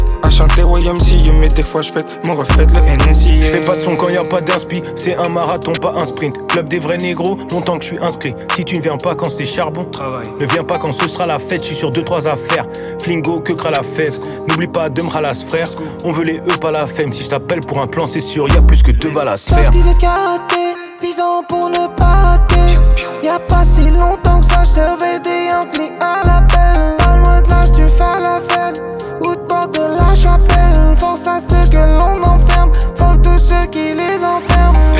Жанр: Рэп и хип-хоп / Иностранный рэп и хип-хоп